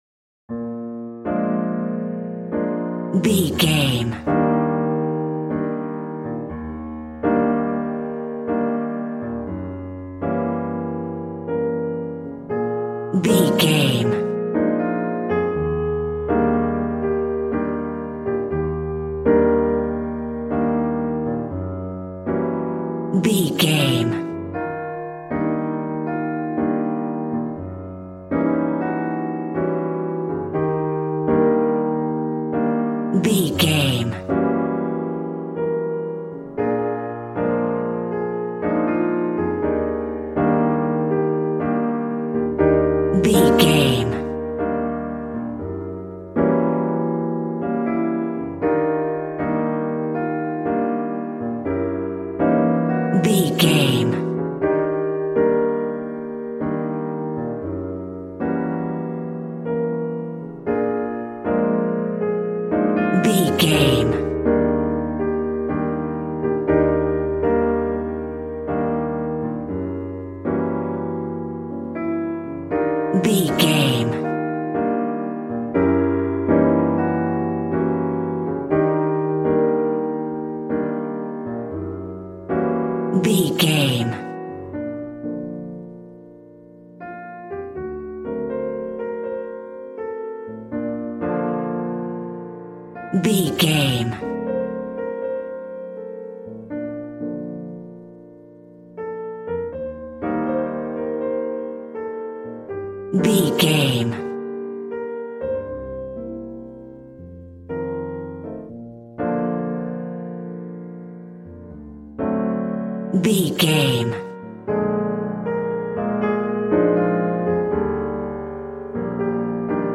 Ionian/Major
B♭
piano
drums